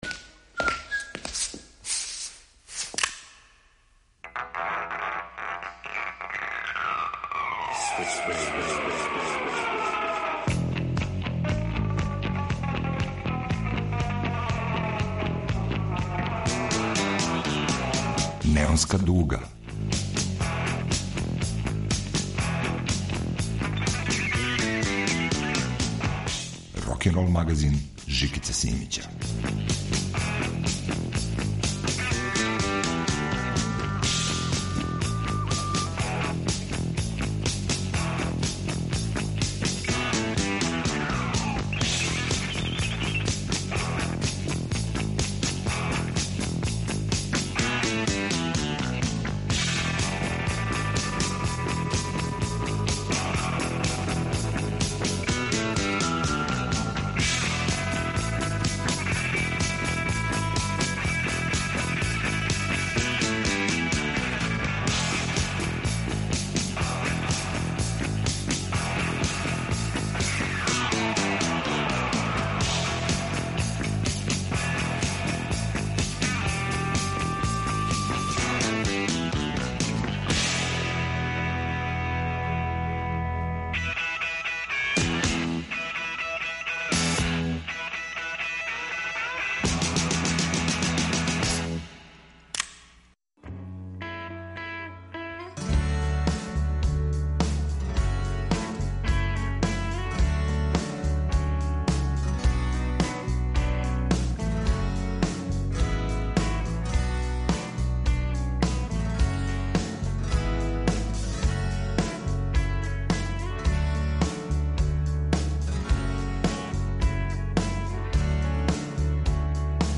Muzički koktel napravljen od pesama različitih žanrova, ritmova i stilova.